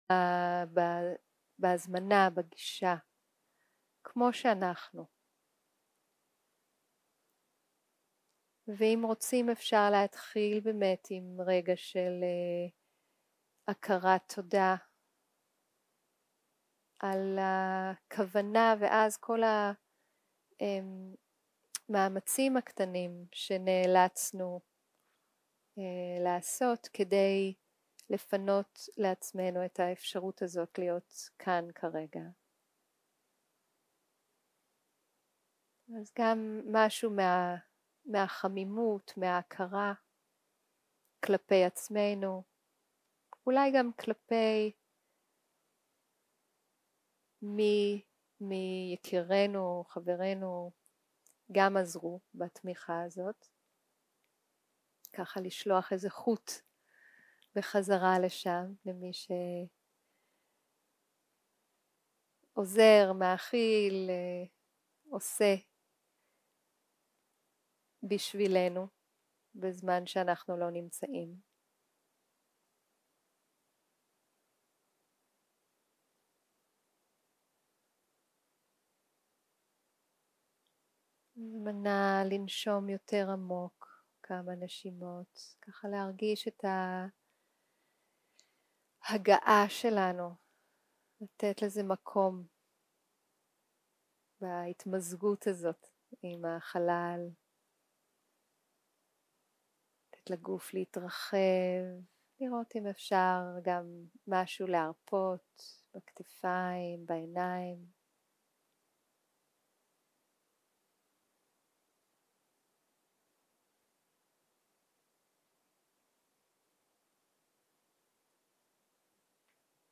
יום 1 - הקלטה 1 - ערב - מדיטציה מונחית
Guided meditation